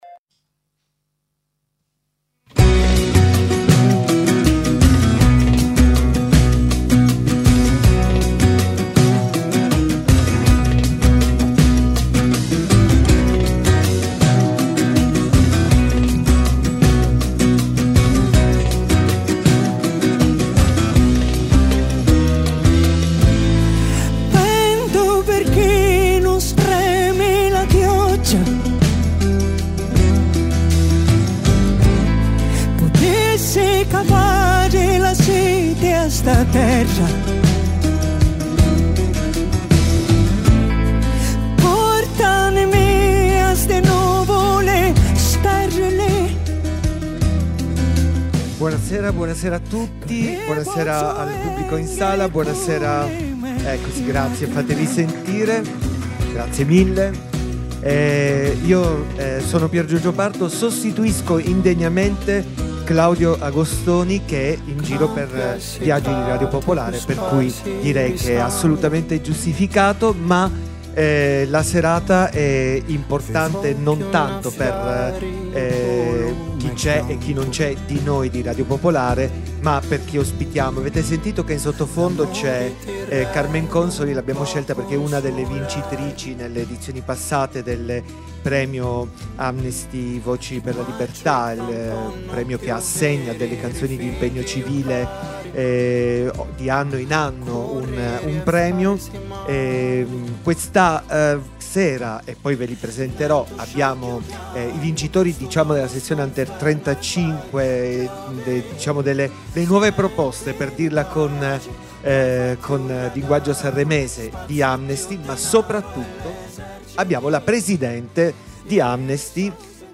Alla serata nell’Auditorium Demetrio Stratos
giovane cantautore e pianista calabrese
rapper e cantautore nativo di Legnano